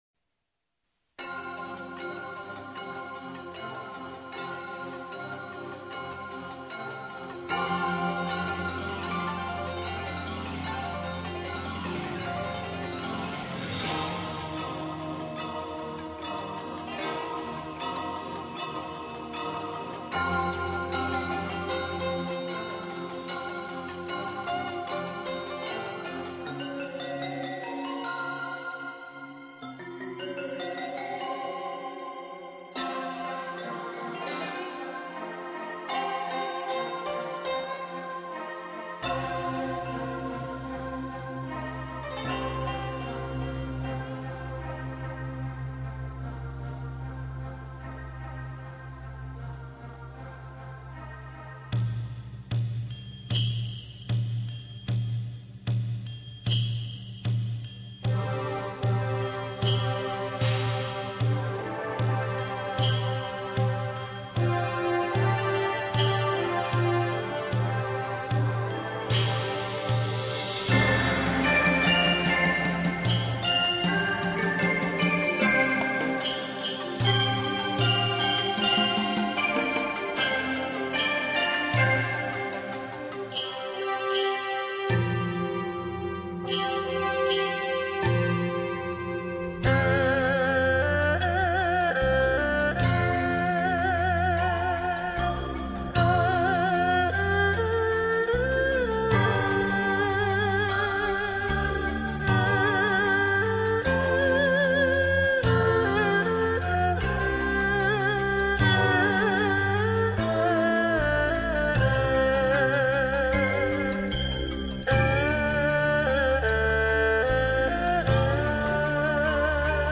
佛音 冥想 佛教音乐 返回列表 上一篇： 看破(古琴